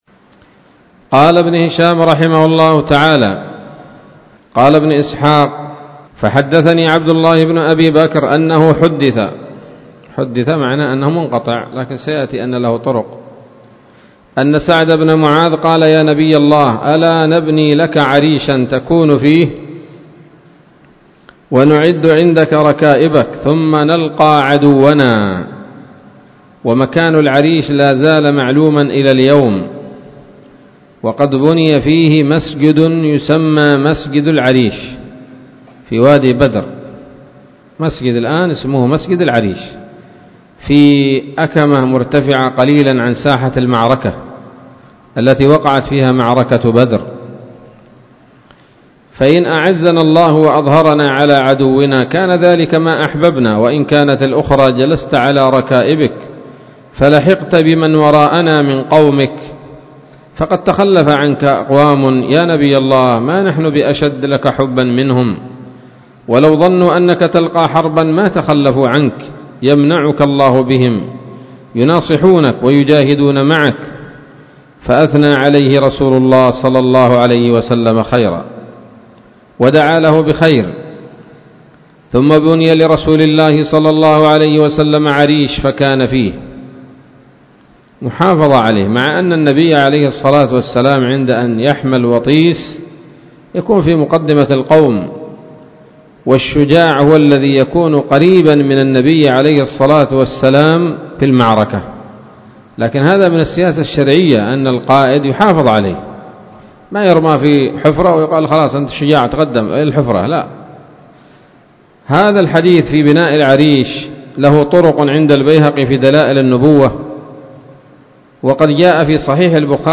الدرس الثالث عشر بعد المائة من التعليق على كتاب السيرة النبوية لابن هشام